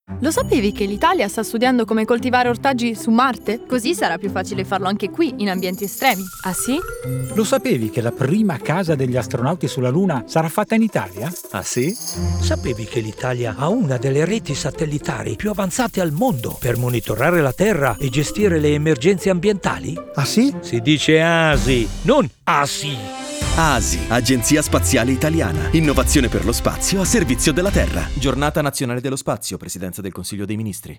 Lo spot radio